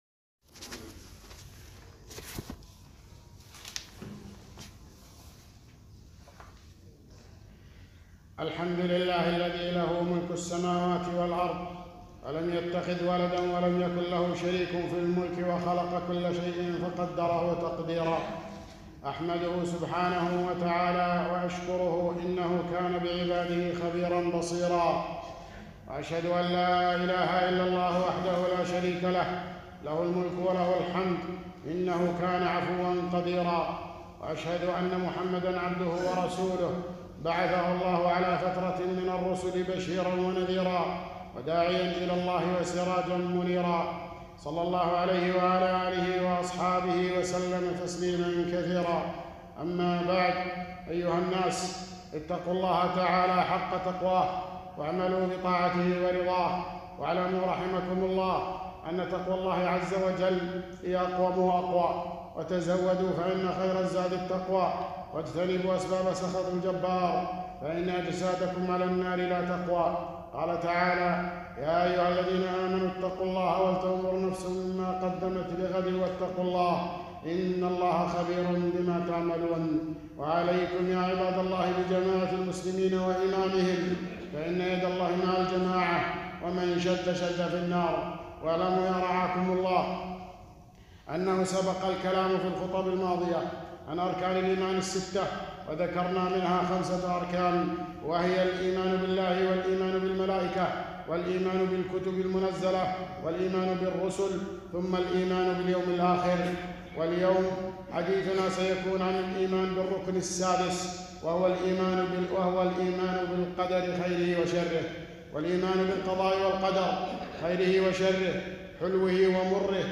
٦- خطبة - الإيمان بالقدر خيره وشره